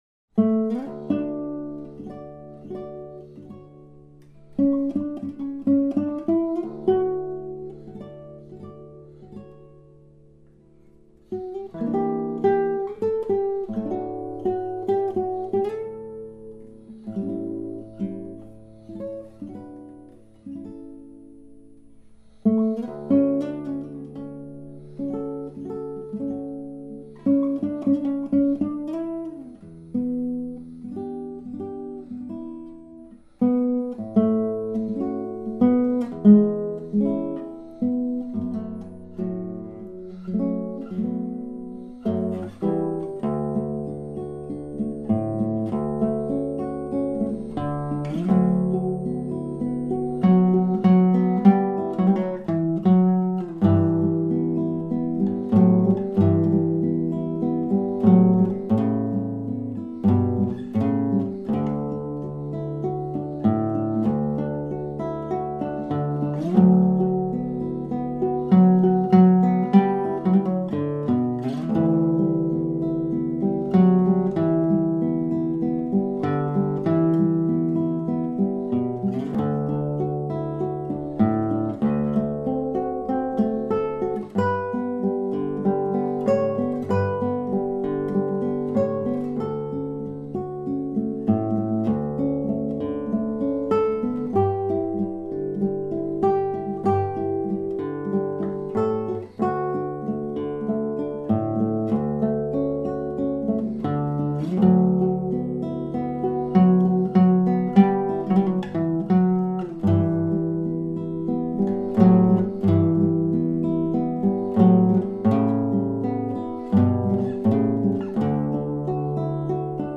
[mp3] Evocation (secondaire) - Guitare Classique